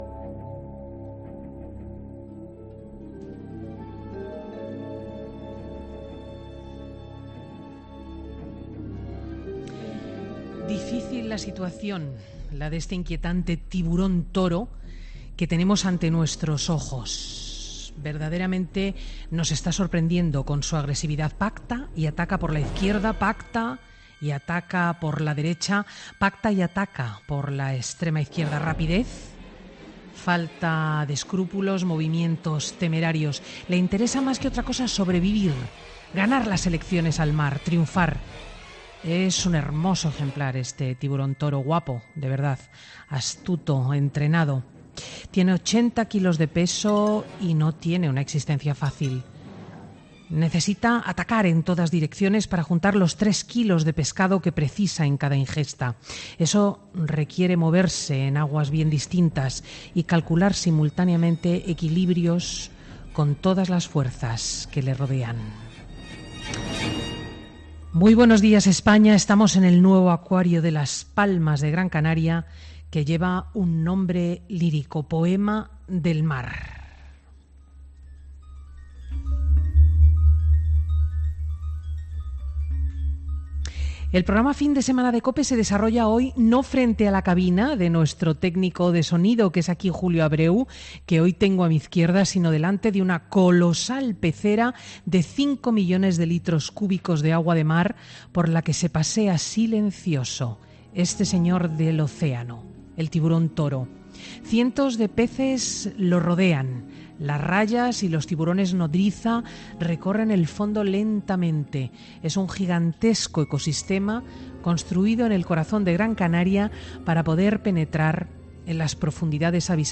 Redacción digital Madrid - Publicado el 30 jun 2018, 10:37 - Actualizado 15 mar 2023, 11:55 2 min lectura Descargar Facebook Twitter Whatsapp Telegram Enviar por email Copiar enlace FIN DE SEMANA , Presentado por Cristina López Schlichting, prestigiosa comunicadora de radio y articulista en prensa, es un magazine que se emite en COPE , los sábados y domingos, de 10.00 a 14.00 horas, y que siguen 769.000 oyentes , según el último Estudio General de Medios conocido en noviembre de 2017 y que registró un fuerte incremento del 52% en la audiencia de este programa.